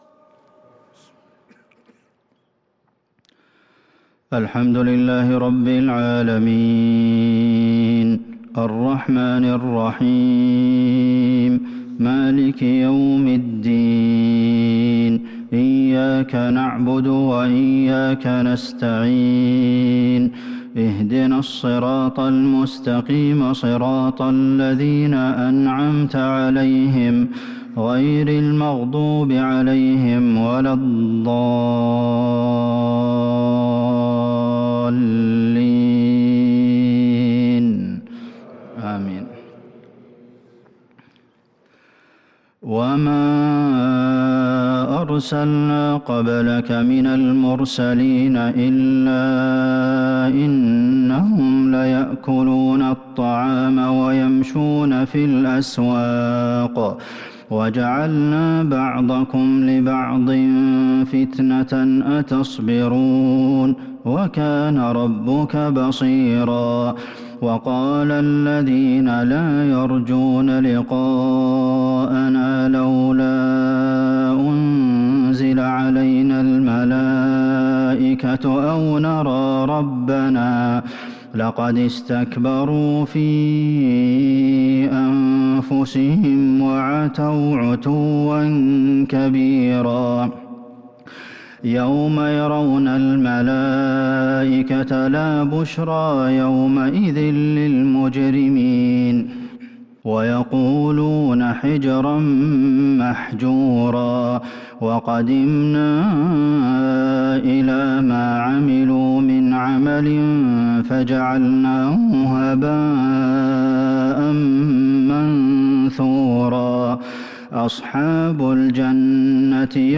صلاة العشاء للقارئ عبدالمحسن القاسم 13 جمادي الآخر 1443 هـ
تِلَاوَات الْحَرَمَيْن .